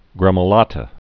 (grĕmə-lätə)